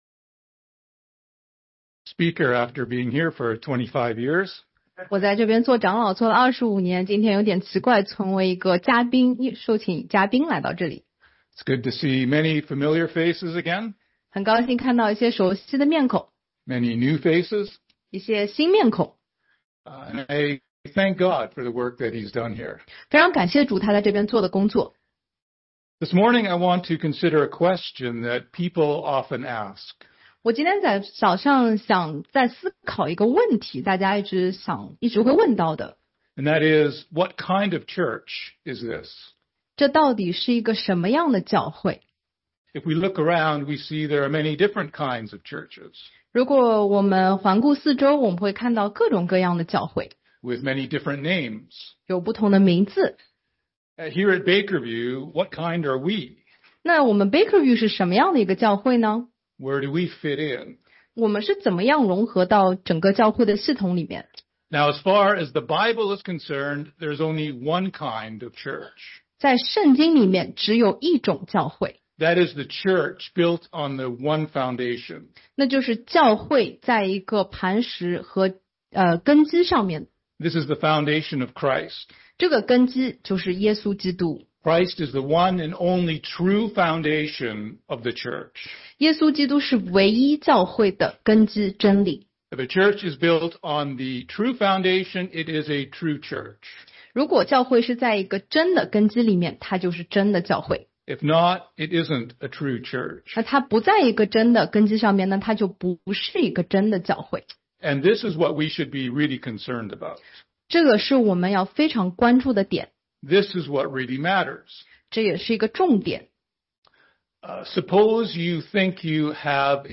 中英文讲道与查经